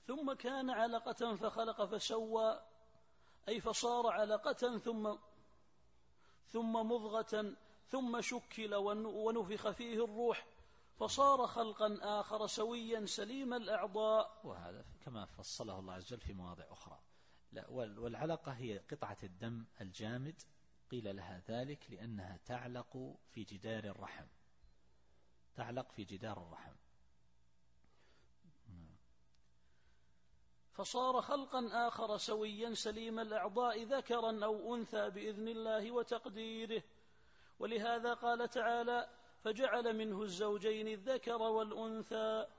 التفسير الصوتي [القيامة / 38]